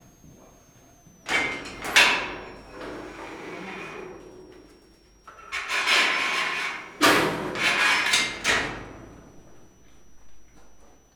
Directory Listing of //allathangok/miskolcizoo2018_professzionalis/sziberiai_tigris/
racsozat_miskolczoo0011.WAV